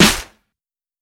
Snare (2).wav